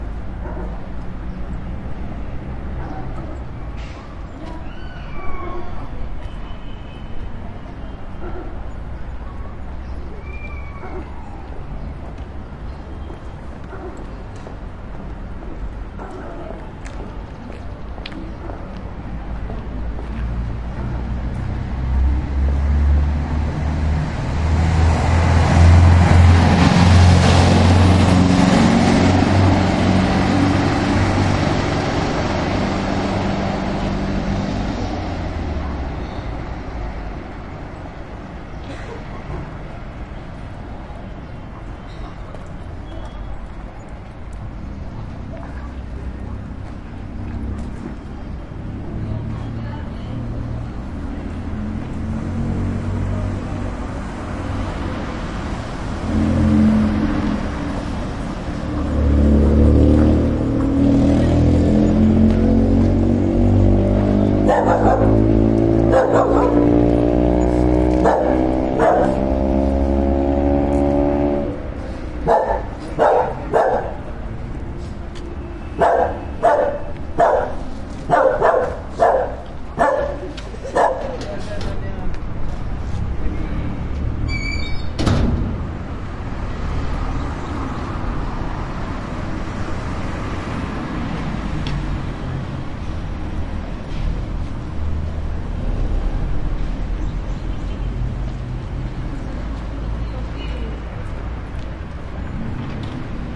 秘鲁 " 侧街曲线住宅区 悸动的汽车汽车或摩托车轻便摩托车通过缓慢和狗吠结束 库斯科，秘鲁，南A
描述：侧街道曲线住宅区蜿蜒的汽车汽车或摩托车助力车通过慢和狗树皮结束库斯科，秘鲁，南美洲
Tag: 美国 汽车 轻便摩托车 摩托车 街道 传球 住宅 面积 汽车 秘鲁